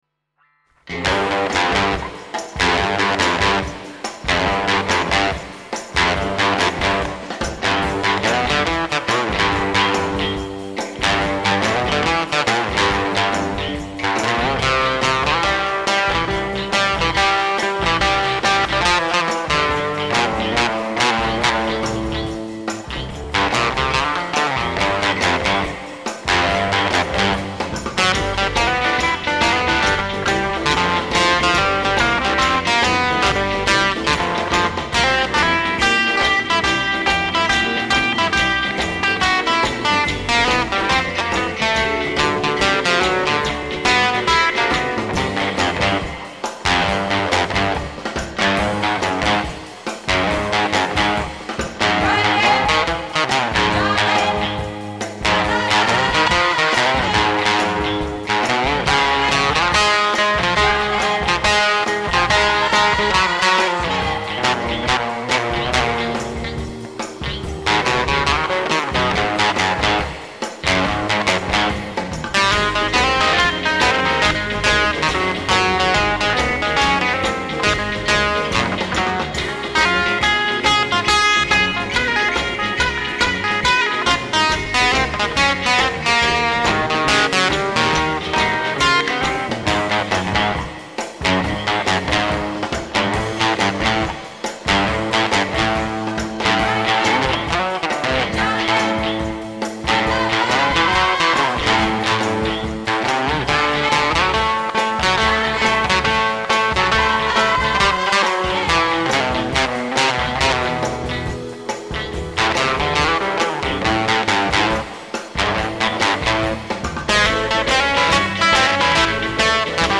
最初の印象が、なんか懐かしい音だなというもので、ソリッドギターにはない温かみのある音です。
…と、思って取り急ぎ７０曲ばかり演奏してから再生してみたら、録音レベルがオーバーしていて音がひずんでいました。
これらの録音はBOSSのＪＳ−１０という装置で、エフェクター内蔵のかなり優れものです。